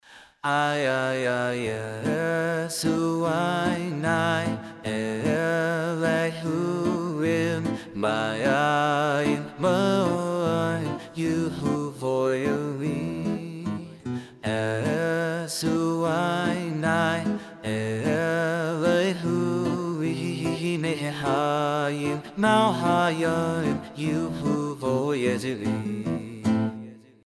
זה קומבינות, כי כאמור הוא קצת חוגג עם הגיה ישראלית… אחר כך שמתי לו קצת אפקטים והוספתי גם קצת גיטרה פחות ממינימלית מאחורה, שלא יהיה משעמם.